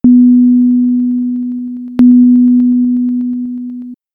The following info and demonstration are useful for both .spc warriors and .smc mages (be warned they are a bit loud):
EXAMPLE 1 (sine wave)
Both of these examples consist of a single held note across the above two bars of volume event data. Note the "pop" sound that occurs halfway, and note the "crackle" sound behind the sine wave in particular (which has no overtones to mask the crackling).
c700_volumecrackle_sine.mp3